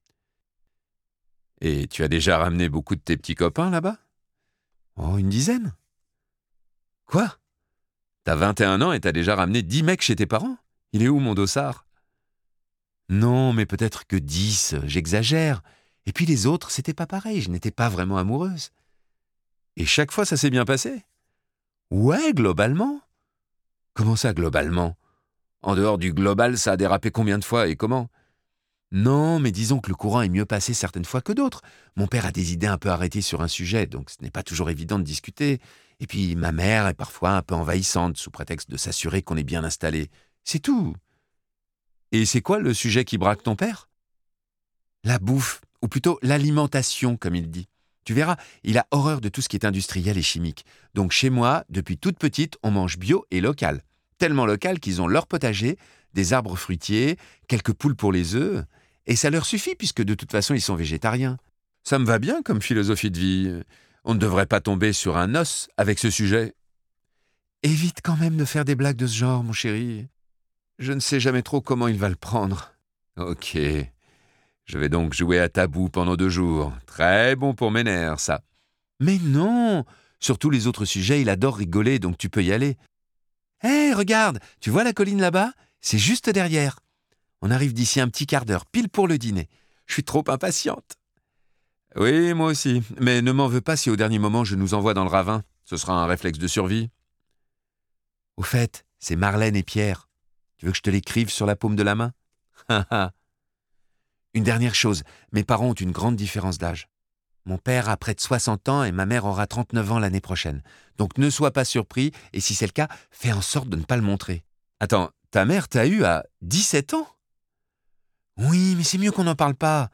Extrait gratuit - La visite - Déguster le noir de Nicolas BEUGLET